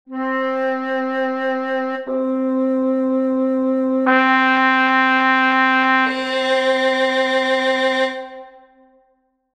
Ascolta la stessa nota eseguita, in successione, dal flauto, dal fagotto, dalla tromba e dal violino:
La differenza timbrica dei quattro strumenti è resa evidente dalle diverse forme d'onda che hanno generato il suono: